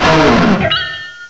cry_not_bastiodon.aif